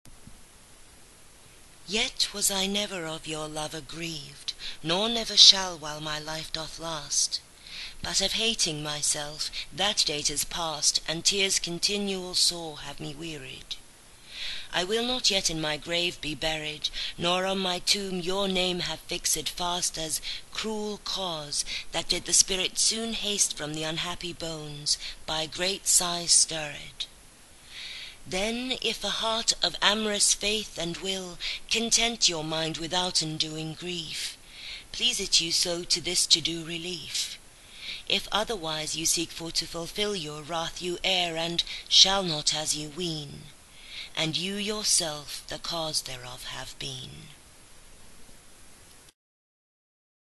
Audio reading